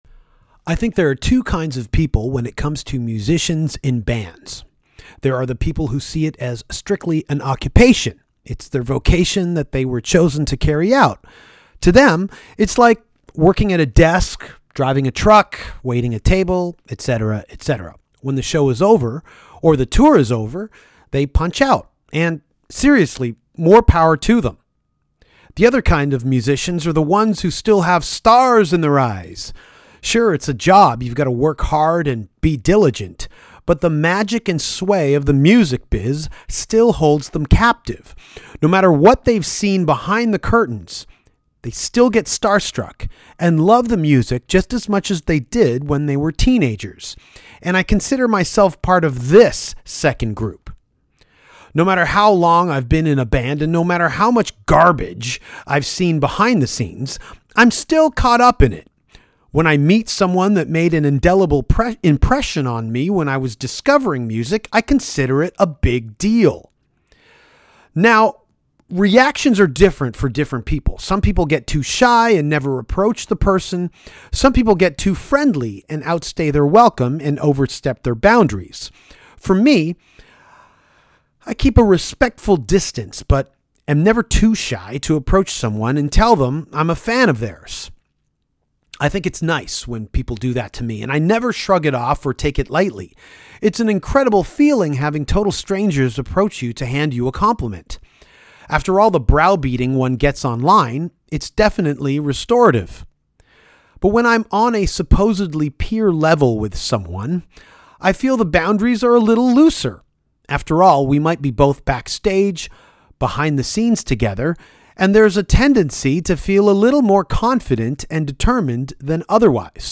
Tommy Victor, lead singer and guitarist for Prong, stopped by the podcast to talk about the beginnings of Prong, CBGB, Glenn Danzig, Samhain and Paul Raven. (special appearance by Derrick Green)